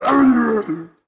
fxFall.wav